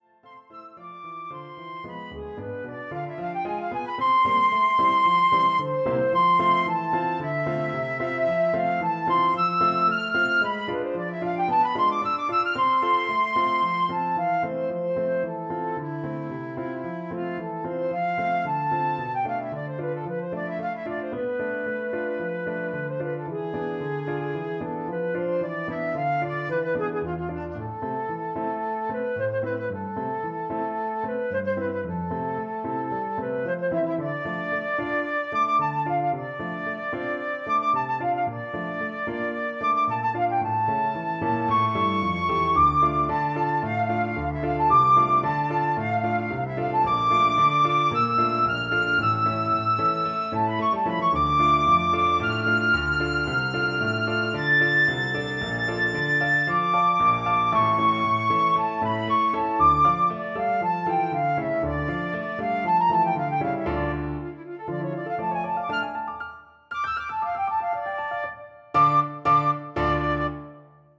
Alle Hör-und Notenbeispiele sind mit dem Notensatzprogramm Sibelius 8.0 generiert worden.
für Flöte und Klavier (2018)
Hörbeispiel Partiturauszug Allegro moderato